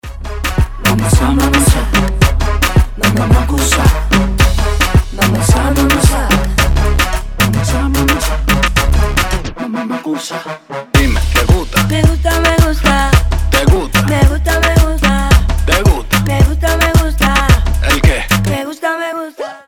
Реггетон ритмичный на звонок от мастера рингтонов